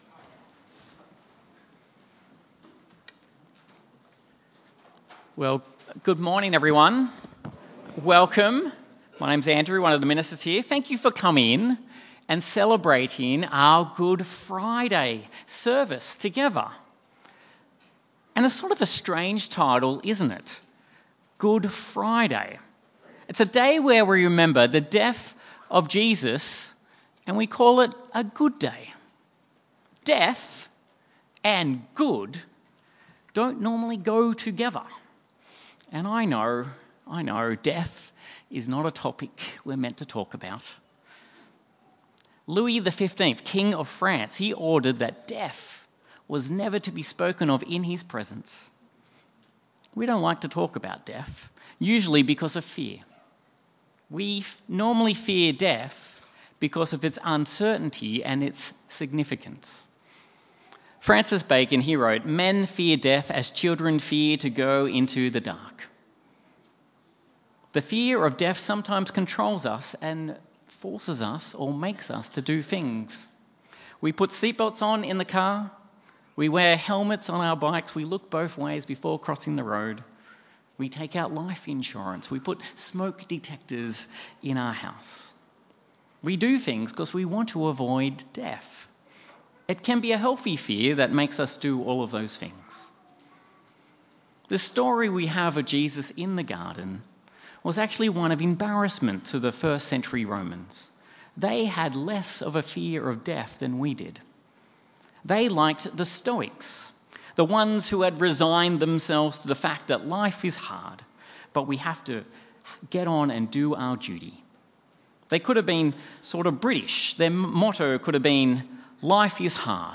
Sermon Series | St Matthew's Wanniassa